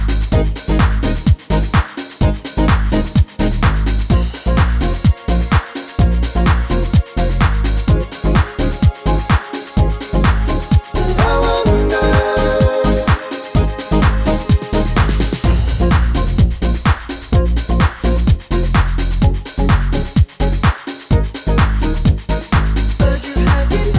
With Intro Click Pop (1990s) 4:21 Buy £1.50